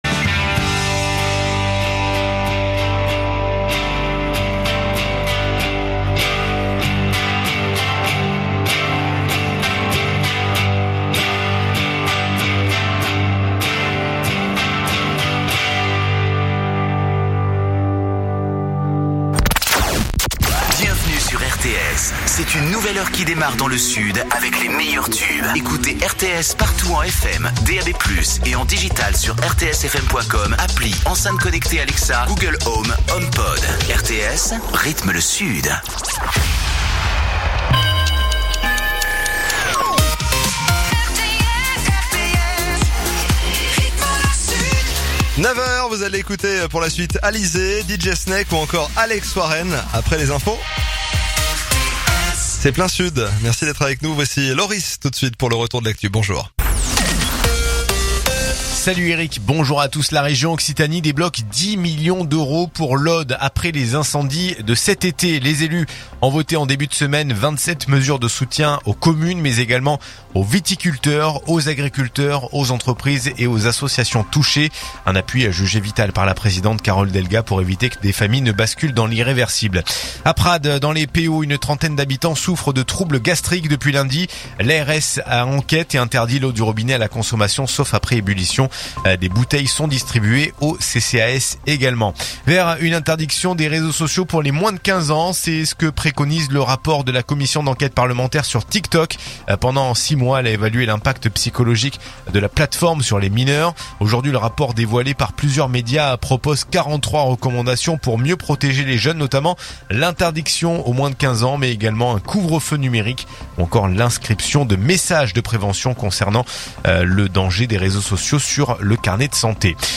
info_narbonne_toulouse_503.mp3